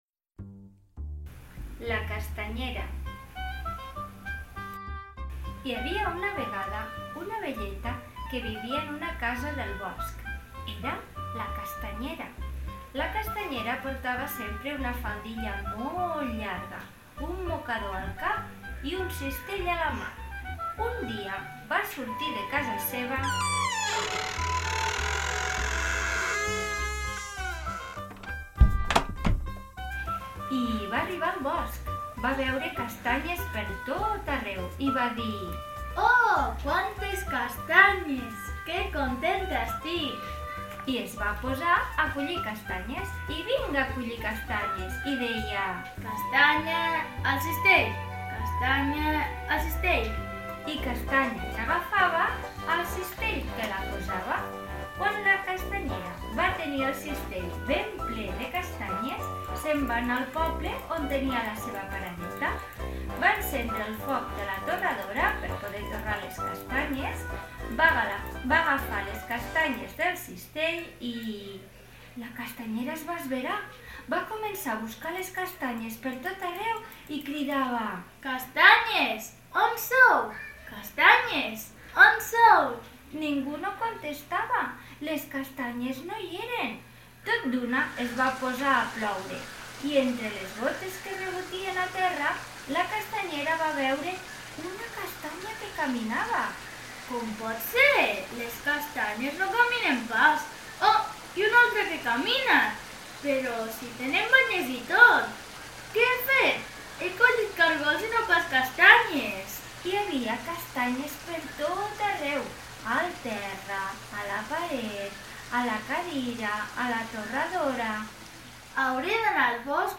Conte infantil: La castanyera